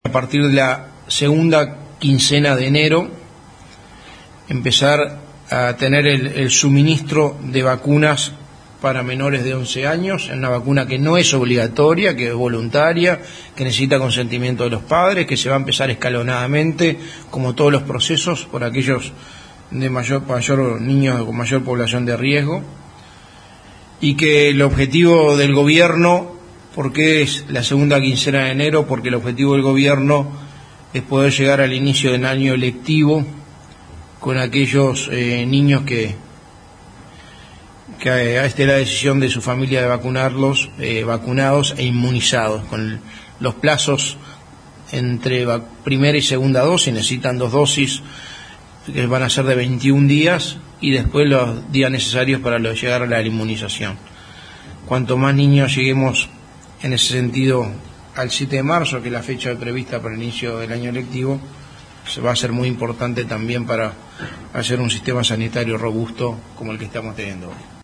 Escuche a Álvaro Delgado aquí